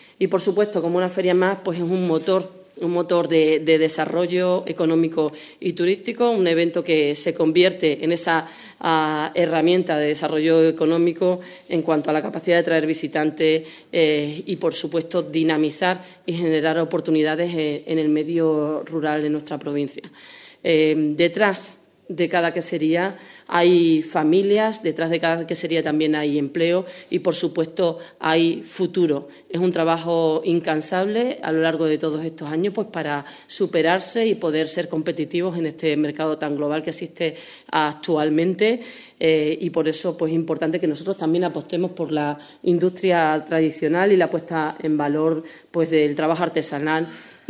Y así lo ha expresado la Vicepresidenta 1ª de la Diputación de Cáceres, Esther Gutiérrez, en la rueda de prensa de presentación a medios de la programación de esta Feria que tendrá lugar los días 28 y 29 de marzo en esa localidad.